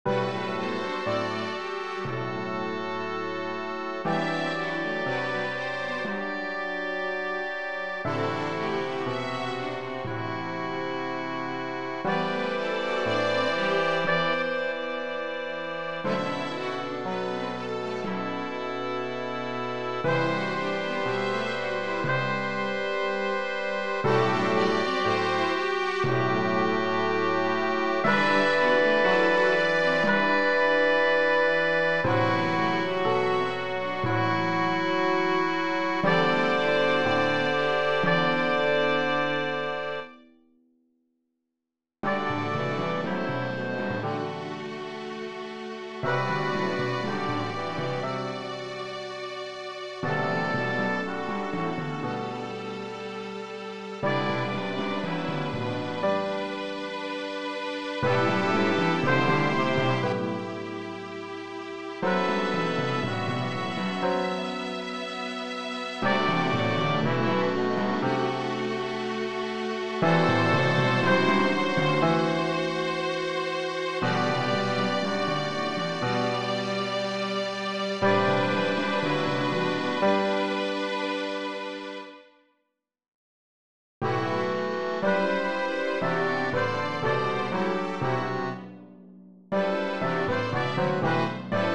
The Modern Symphony Music Prose Original Compostion.